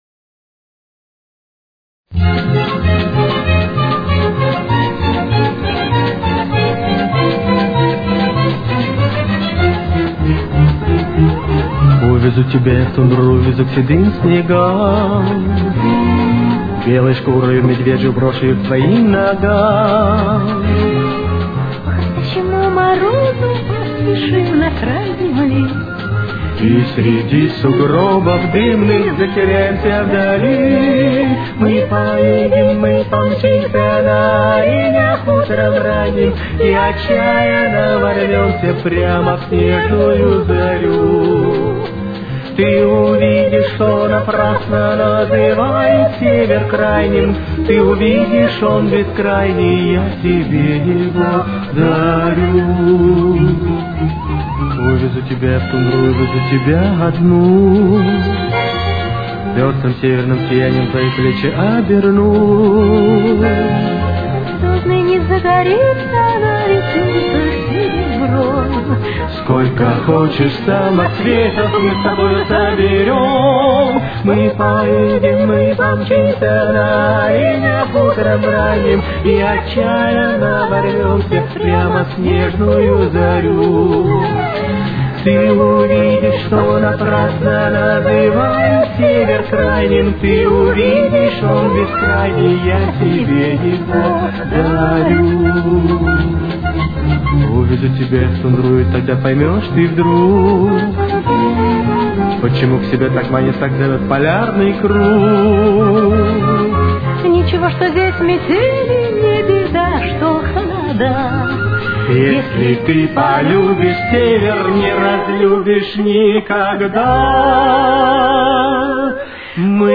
Темп: 200.